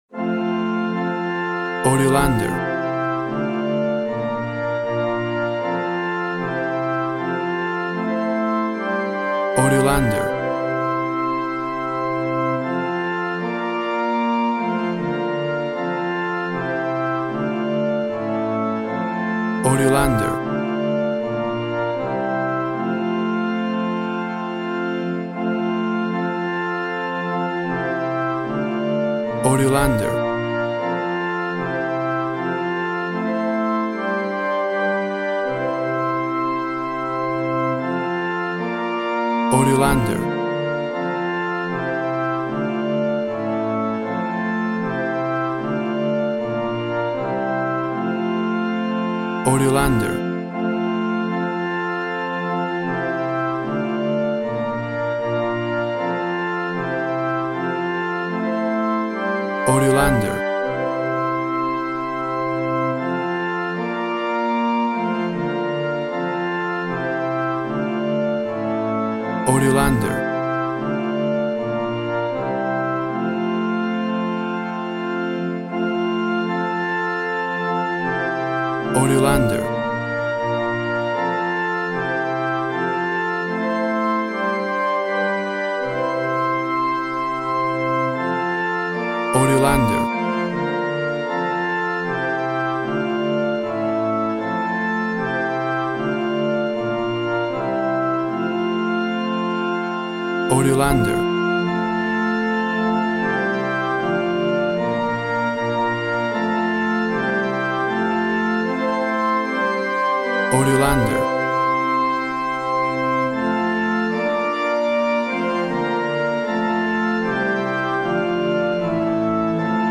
WAV Sample Rate 16-Bit Stereo, 44.1 kHz
Tempo (BPM) 75